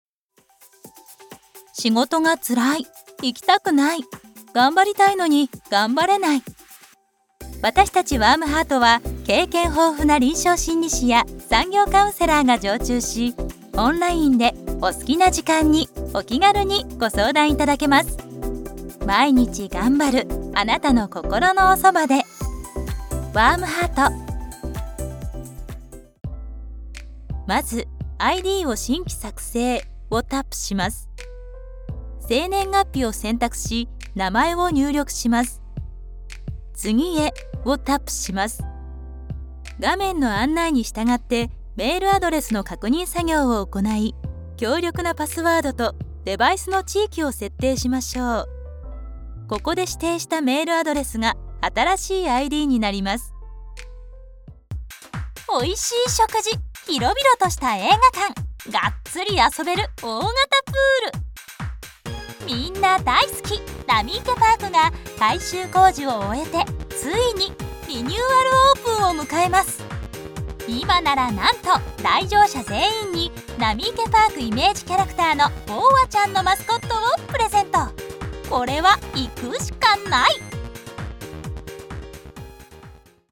軽快・重厚・ナチュラル・上品・ドラマチック・お笑い・キャラクター。柔らかな中低音が七変化。
ボイスサンプル
• 【中音高音3種】サービス紹介・説明・ハイテンション